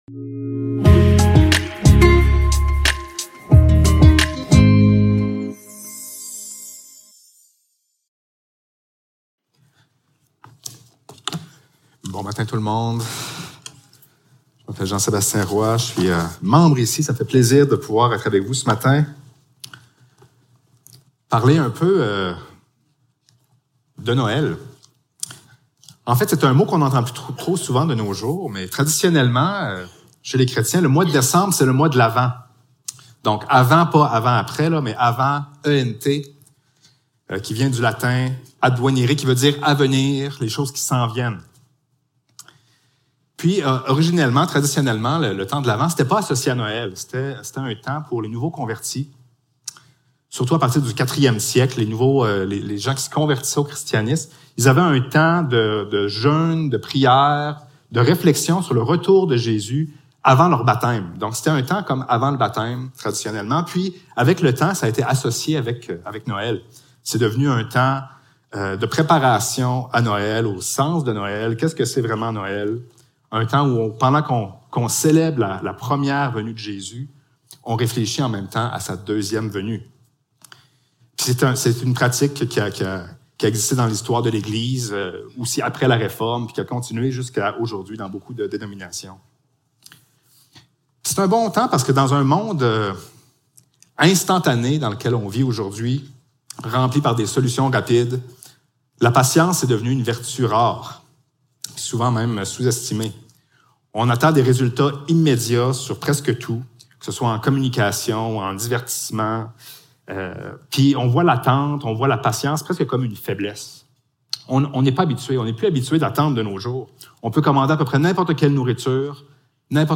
Service Type: Célébration dimanche matin